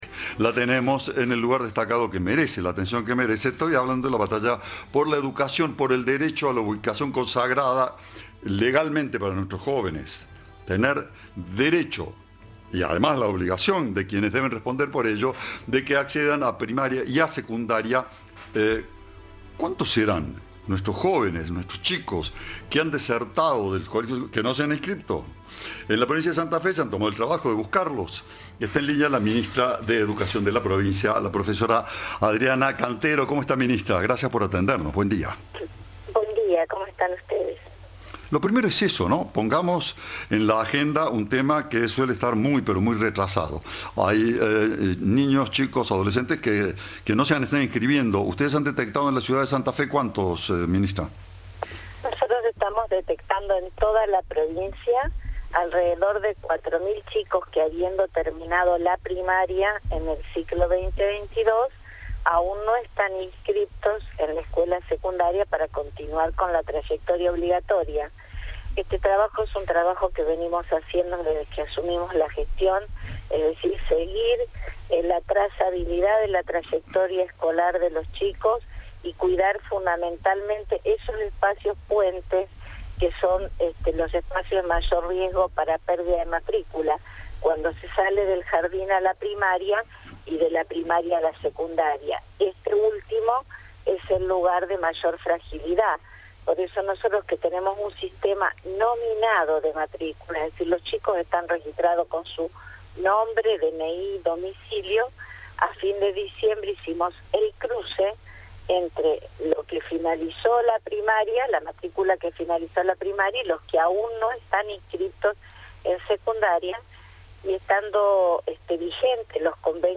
Adriana Cantero, ministra de Educación de Santa Fe, dijo a Cadena 3 que se trata de alumnos que terminaron la primaria, pero que aún no se anotaron para seguir con el trayecto educativo obligatorio.
Entrevista